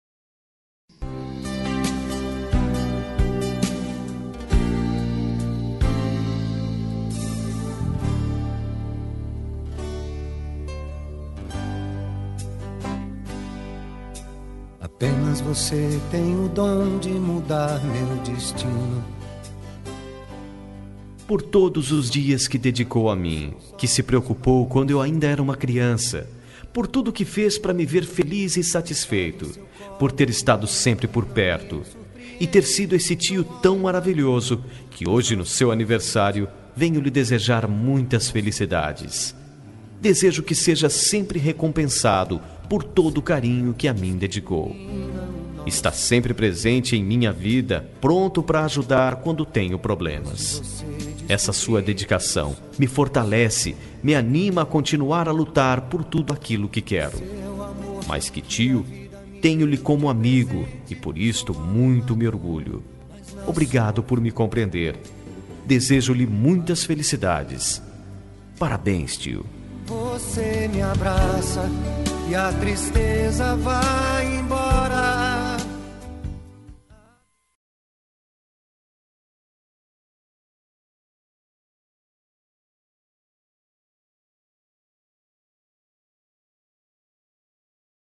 Aniversário de Tio – Voz Masculina – Cód: 904
904-tio-masc.m4a